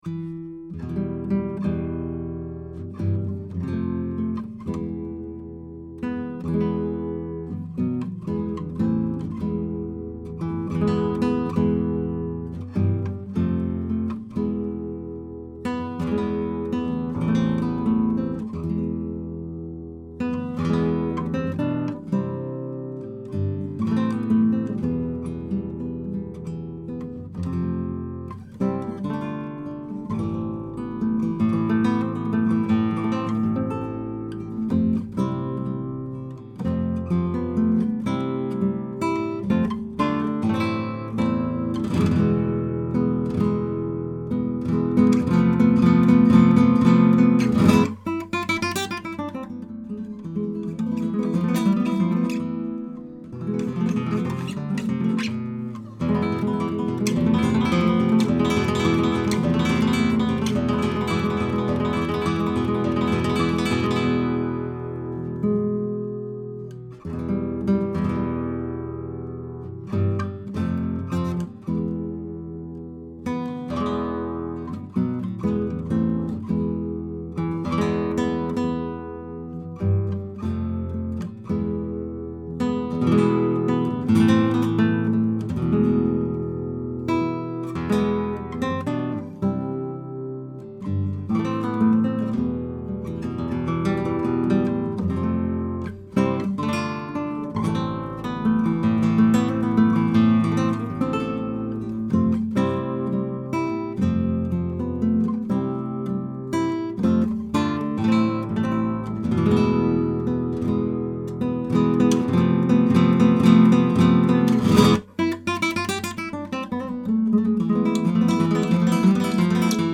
Here are 39 quick, 1-take MP3s of these mics into a Presonus ADL 600 preamp with a Rosetta 200 A/D converter. This is straight signal with no additional EQ or effects:
10-STRING CLASSICAL HARP GUITAR: